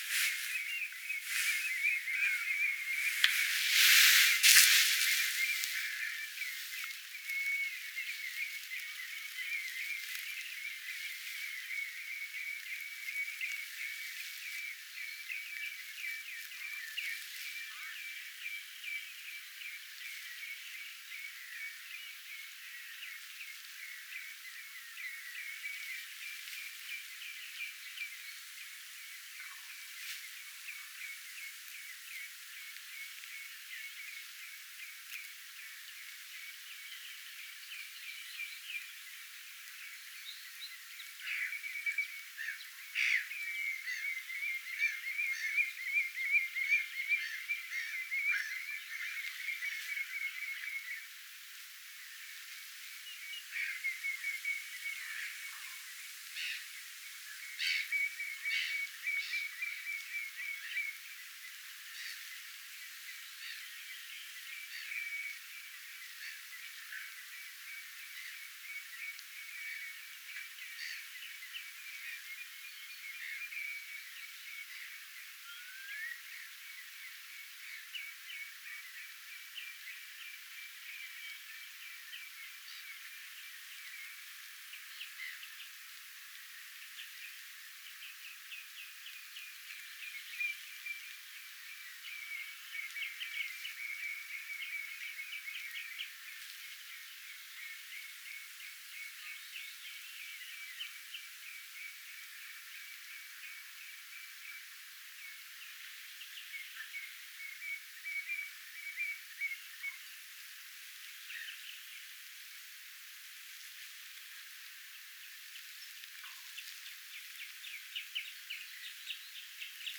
punajalkaviklot ääntelevät
punajalkaviklot_aantelevat.mp3